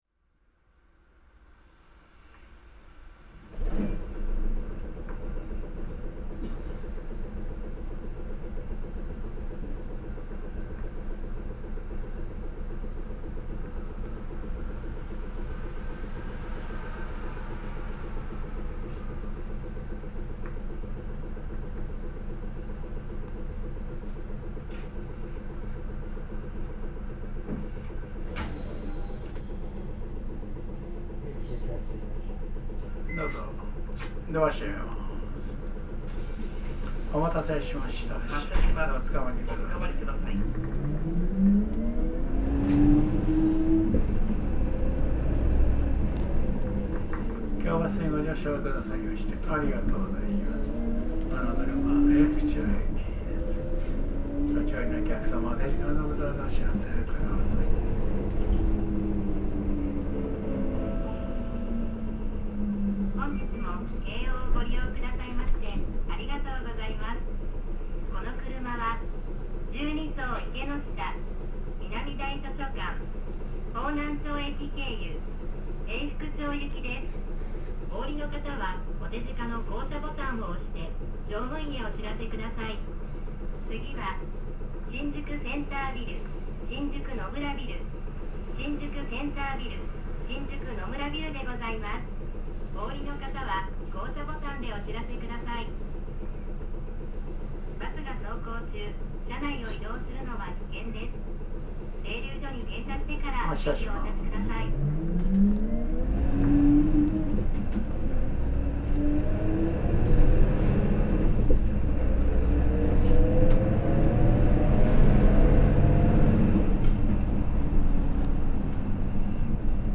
京王バス いすゞ QQG-LV234L3 ・ 走行音(全区間) (39.0MB★) 収録区間：方南線 宿33系統 新宿駅西口→永福町 収録当時永福町営業所に所属していた初代エルガハイブリッドである。モーターのみの力で発進でき、直角カルダン駆動の電車のようなモーター音が特徴であったが、保守に難があったのか京王バスでは全車が早期に除籍されてしまった。